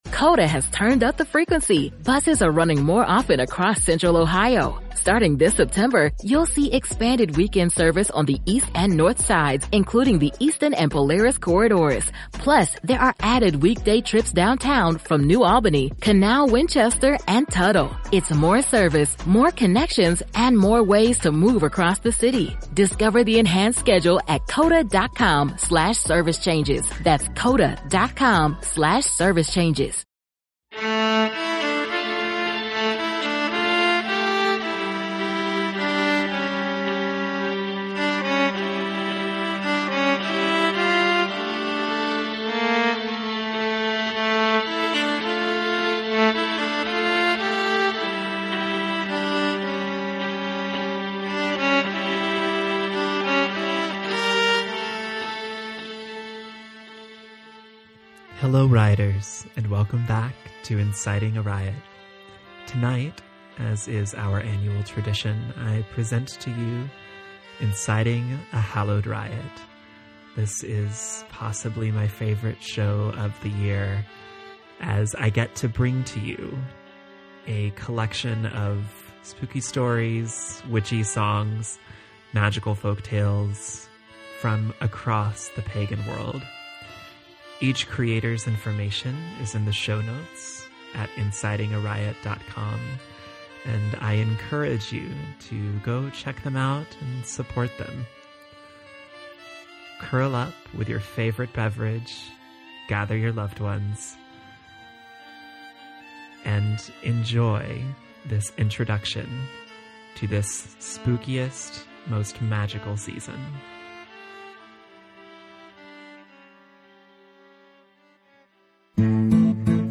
Welcome back to our annual celebration of stories and songs from across the Pagan world. Each of the storytellers and musicians is listed below.